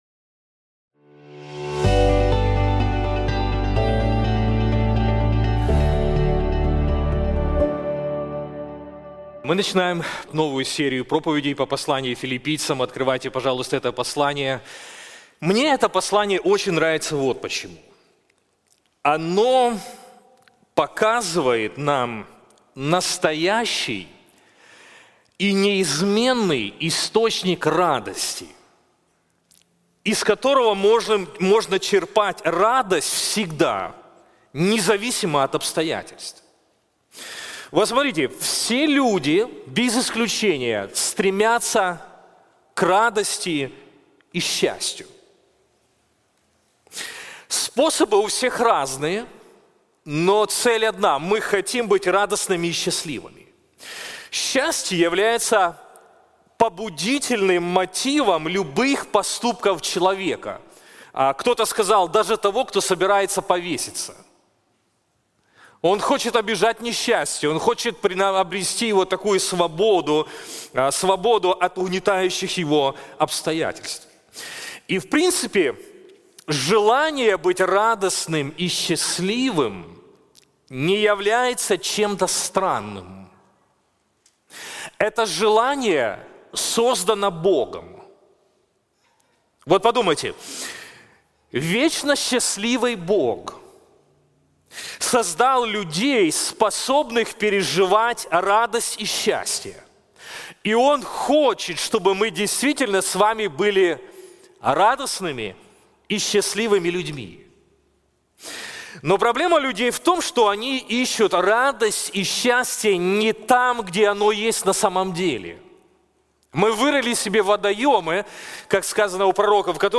Первая проповедь из серии по Посланию к Филиппийцам - 'ПОСЛАНИЕ РАДОСТИ'